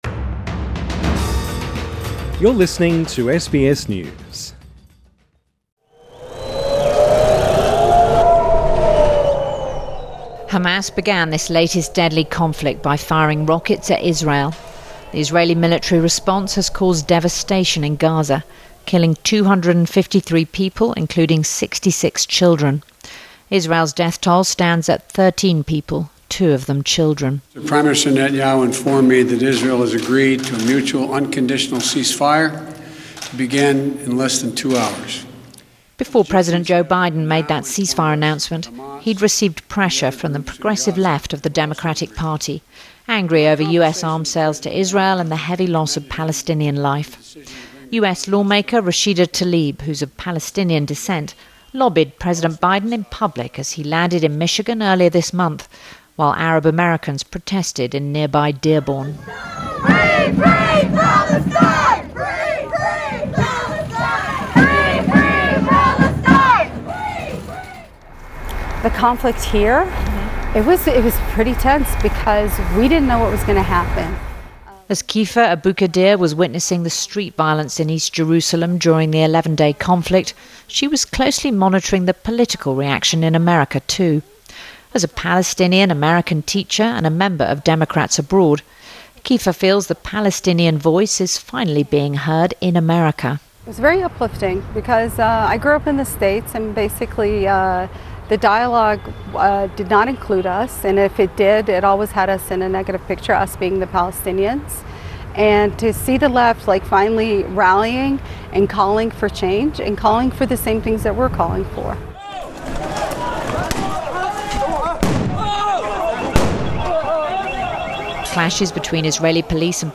This feature was first broadcast on the BBC World Service